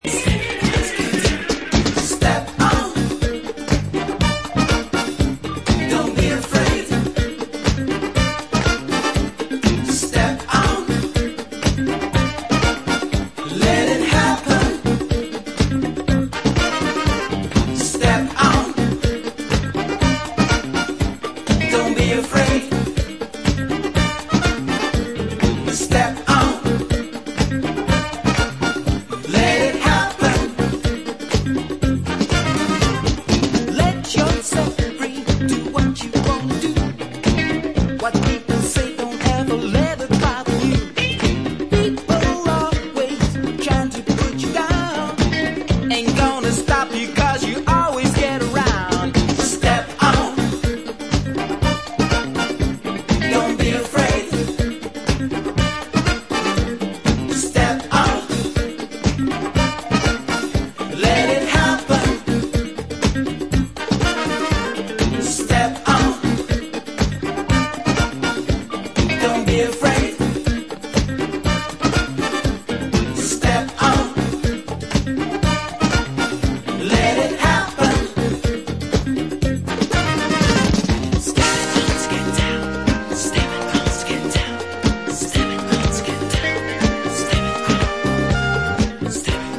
funky Afro disco monsters
Top quality disco!